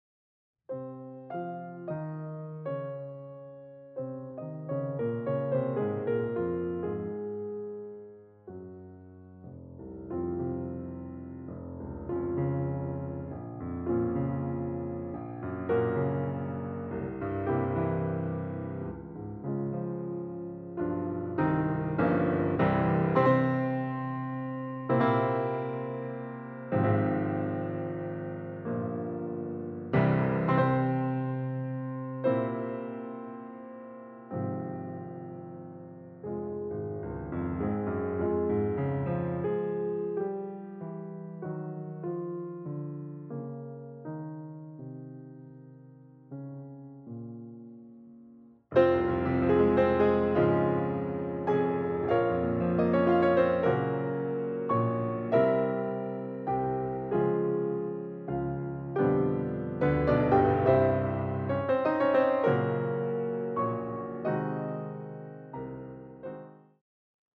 Voicing: Bb Clarinet Collection